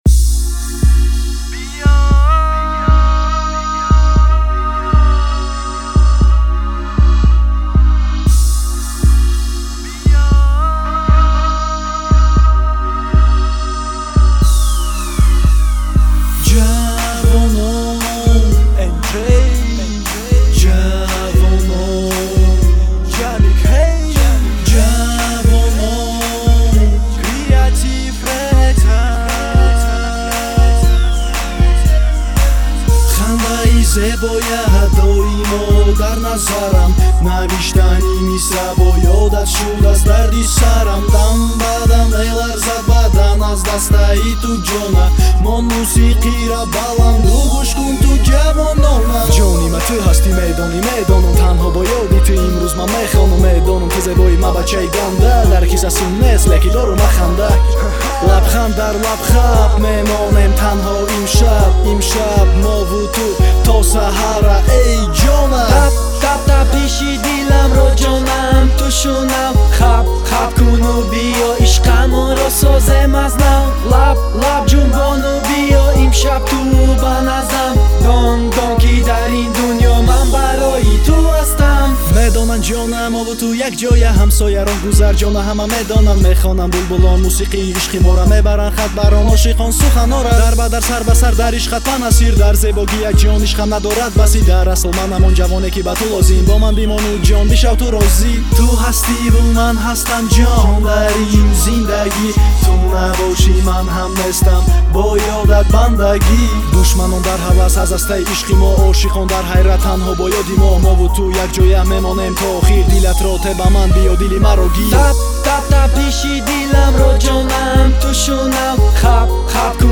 Рэп / HIP HOP